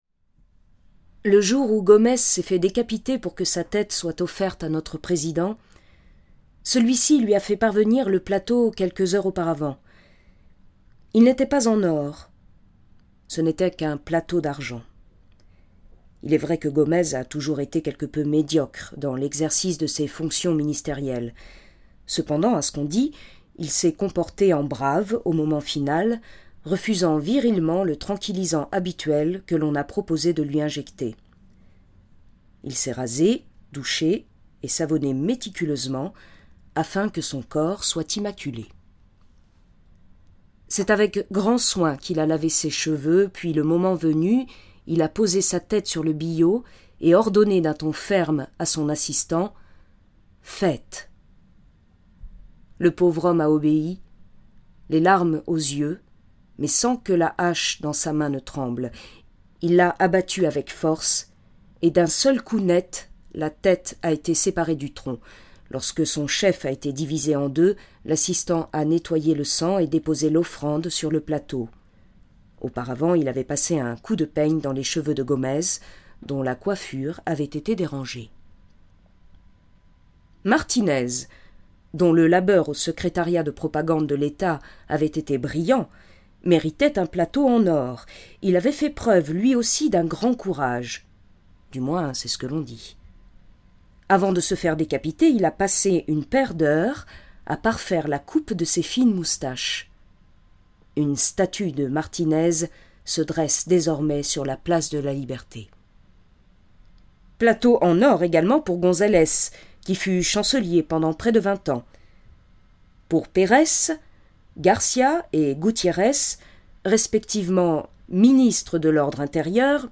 Extrait lu